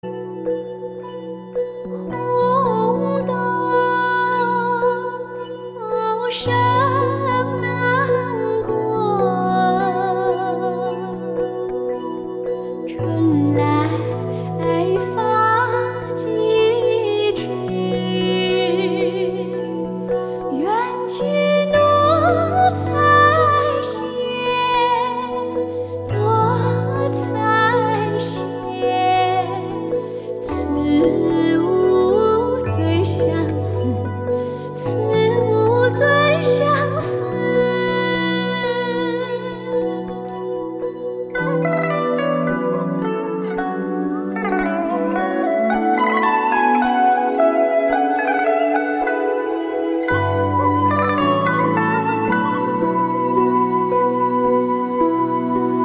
中國音樂、發燒天碟